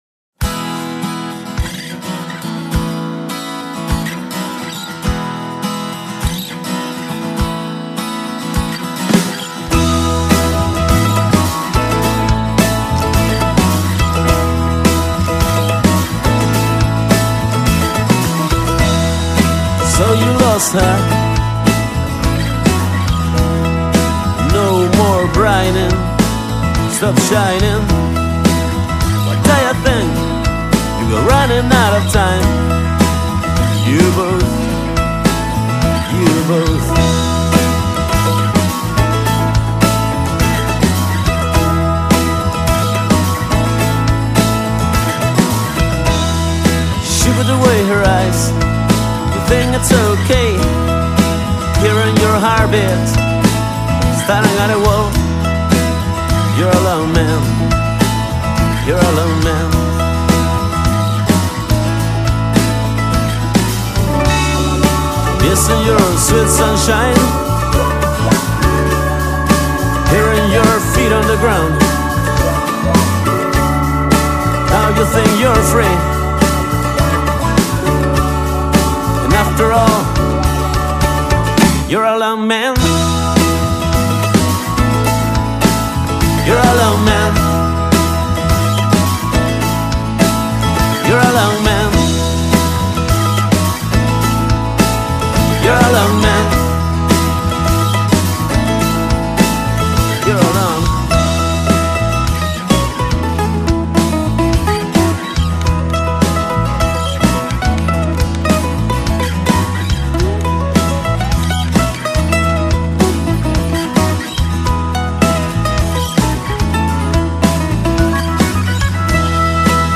Mandolina